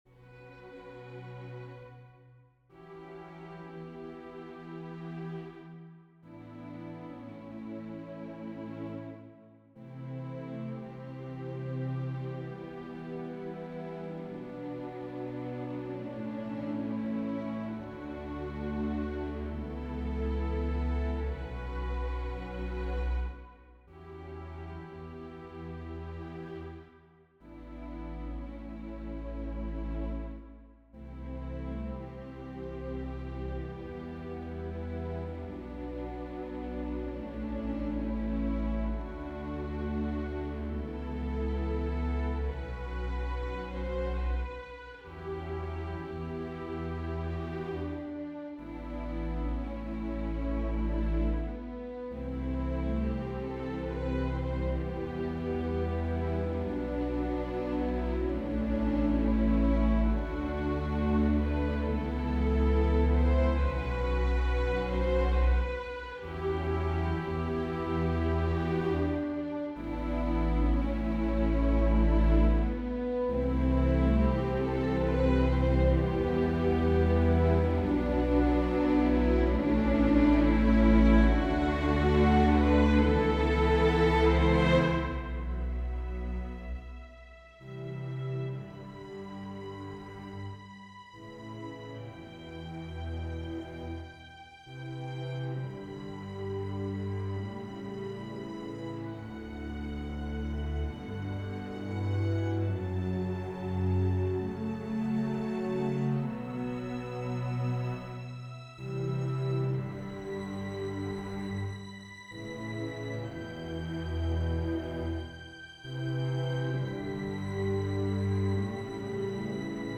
Strijkorkest (2025)